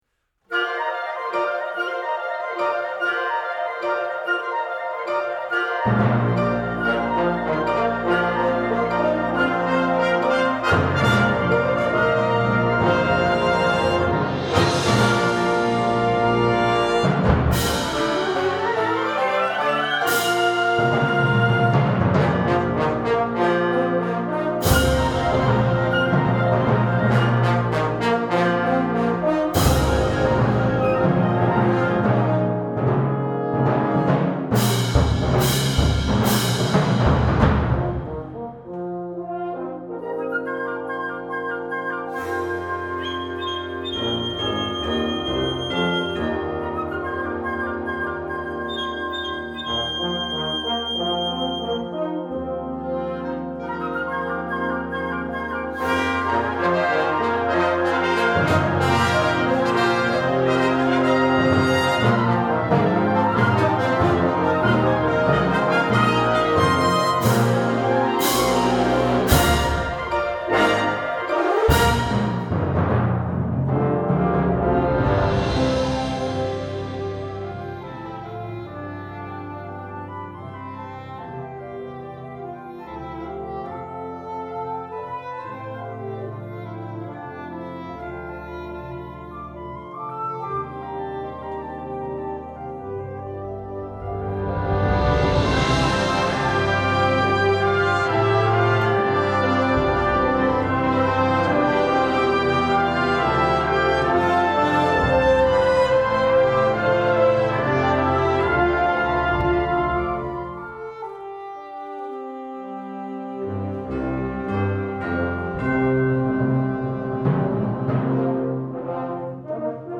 Genre: Band
Piccolo
Bb Trumpet 1
Chimes
Timpani (4)
Suspended & Crash Cymbals